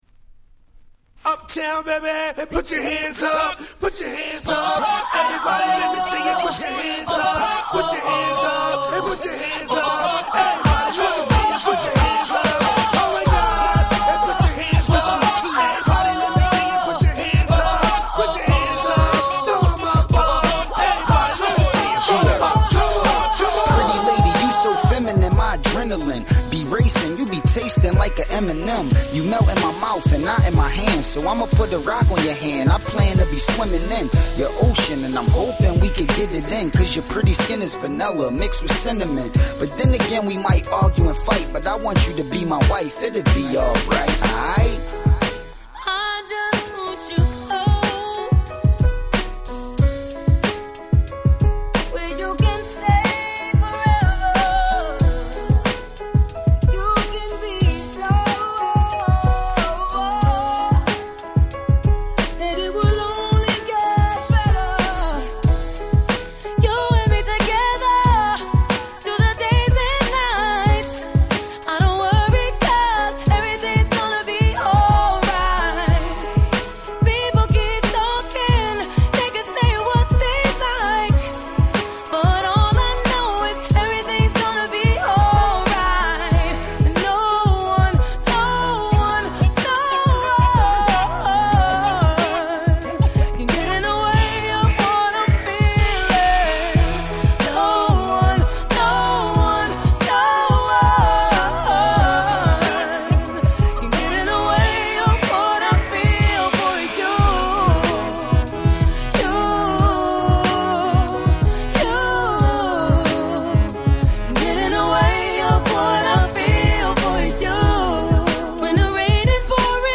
07' Big Hit R&B !!